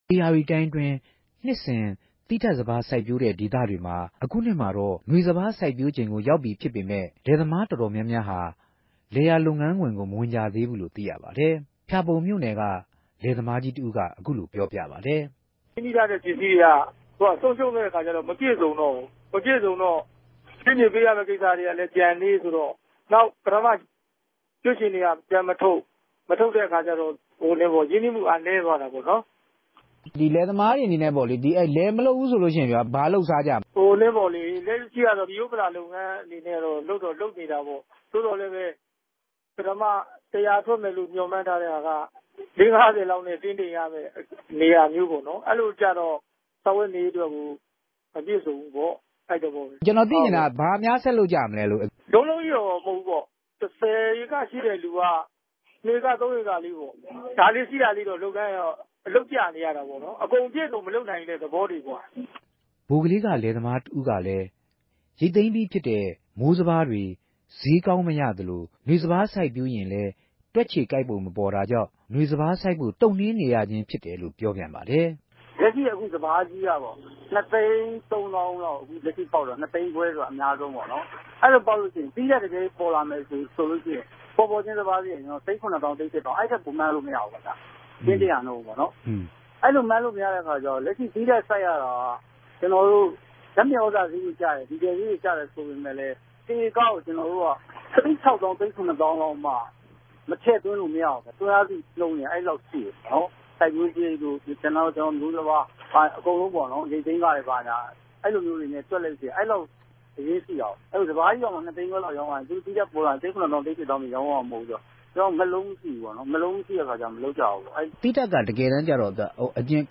လယ်သမားတဦး။ "စားဝတ်နေရေးလည်း မေူပလည်တဲ့အခၝကဵတော့ ထြက်မလုပိံိုင်သေးဘူး၊ ၁၀ ဧက လုပ်တဲ့ သူက ၃၊ ၄ ဧကပေၝ့နော်၊ အဲလိုတြေ စားသောက် လုပ်ကိုင်နေုကရတယ်။ လက်ရြိ ၂ဋ္ဌကိမ်စိုက်တြေ၊ ၃ ဋ္ဌကိမ်စိုက် တြေ တခဵိြႛကဵတော့လည်း ပင်္စည်းဆုံးရံြးတာတိုႛ ရင်းိံြီးူမြပိံြံမြ ထပ်္ဘပီးတော့ မရတော့ဘူးပေၝ့၊ အဲဒၝေုကာင့် ဆုံးရံြးုကတာ။"